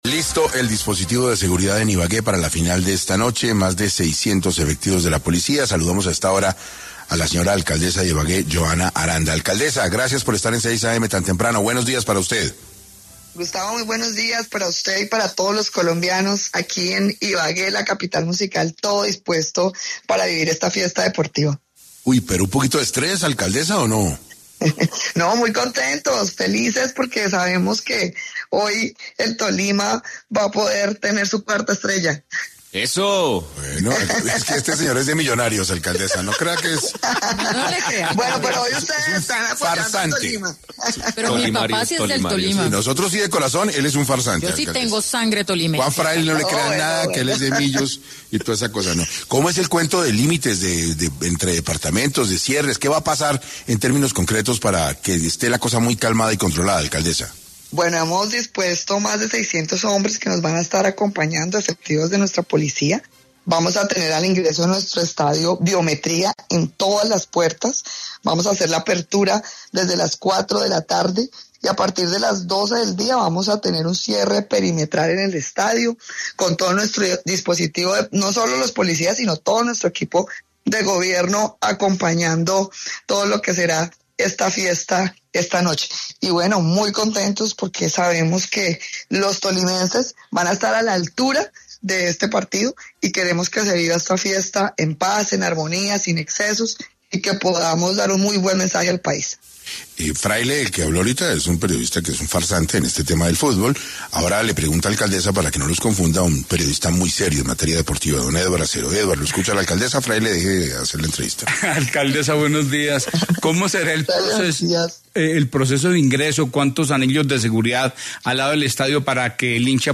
La alcaldesa de la ciudad, Johana Aranda, habló en ‘6AM’ sobre las medidas de seguridad que tendrá el estadio Manuel Murillo Toro.